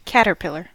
Ääntäminen
IPA : /ˈkætəpɪlə(ɹ)/